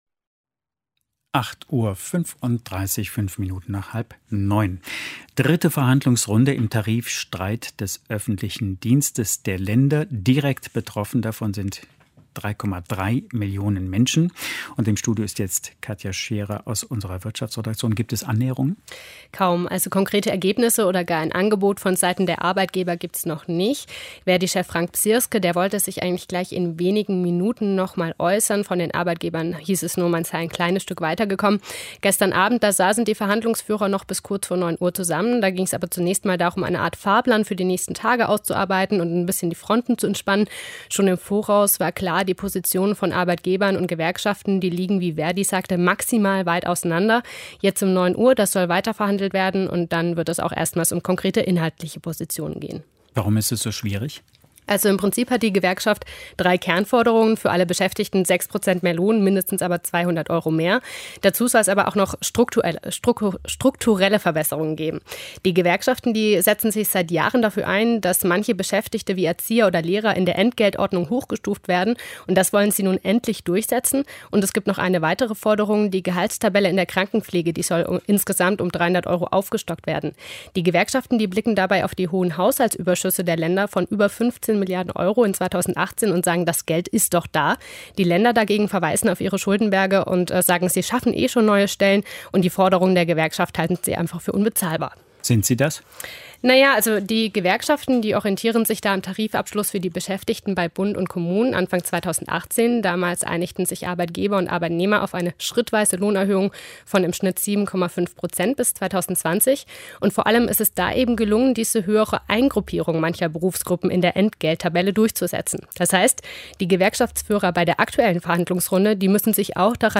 Das Wirtschaftsgespräch: Tarifverhandlungen im öffentlichen Dienst treten auf der Stelle